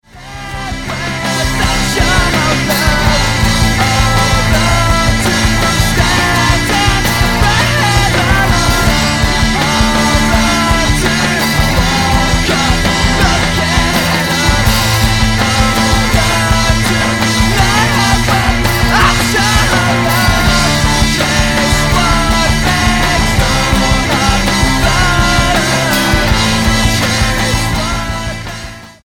STYLE: Rock
abrasive, post punk, emo rock